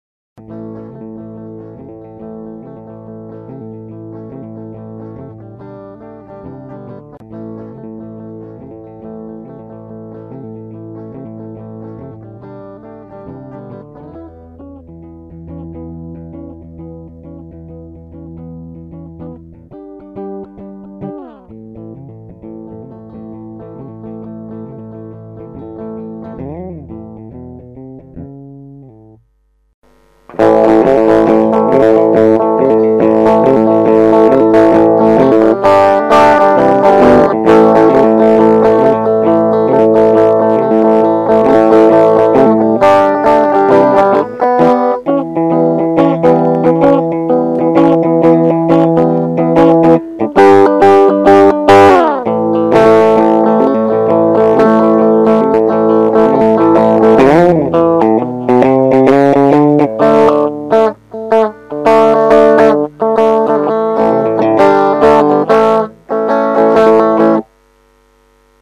Gli esempi audio sono stati registrati usando la mia Red Special (quella della sez. Chitarra& Ampli) e un amplificatore valvolare VOX AC 30 Top Boost (quello dietro).
NB: I file contengo i prezzi eseguiti 2 volte di fila; PRIMA SENZA l'uso del Treble e POI CON il Booster Cornish-like TB-83.
Le registrazioni sono state effettuate microfonando l'amplificatore e NESSUN ALTRO EFFETTO è stato utilizzato.
Rythm 1                  FULL        Middle & Bridge In Phase
May Style-Ritm.mp3